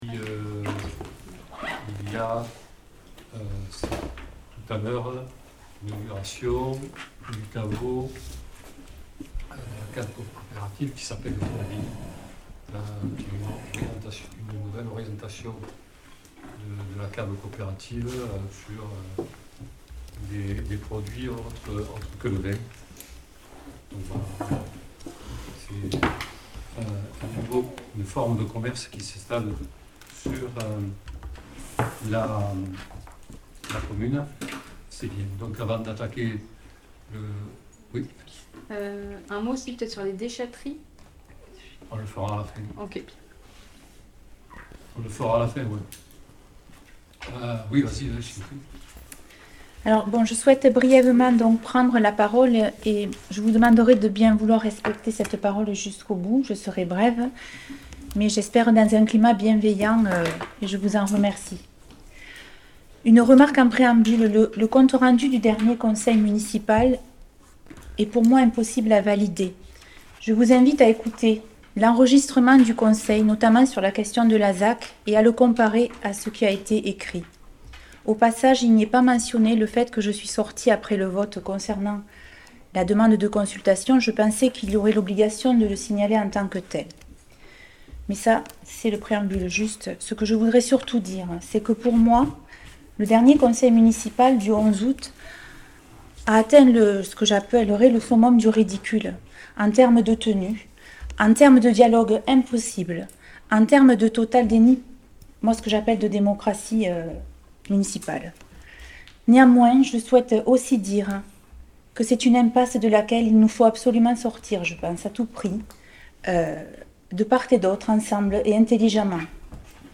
Conseil municipal du 11 août 2022
Le Conseil Municipal de Montpeyroux s’est réuni ce 11 août 2022 en mairie à 18h00 pour sa douzième session.